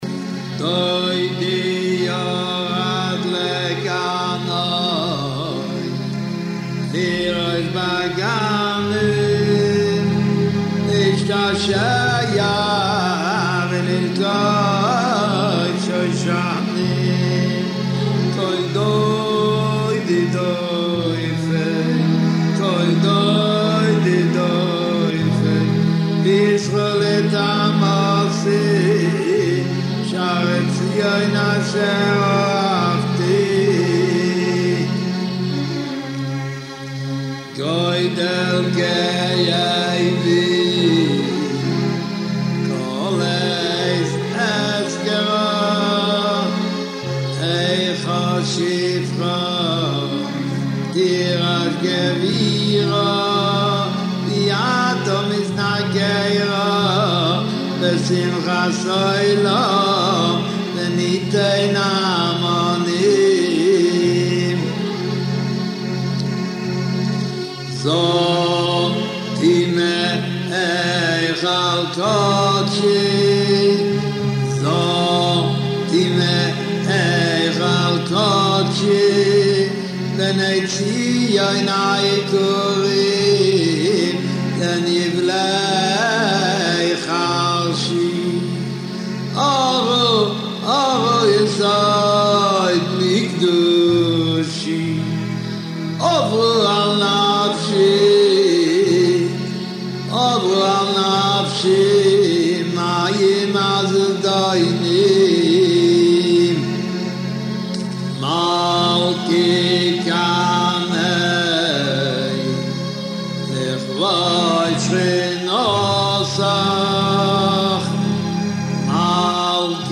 ספק ניגון ספק נוסח